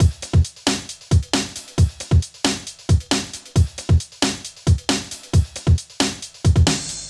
KIN Beat - Full 1.wav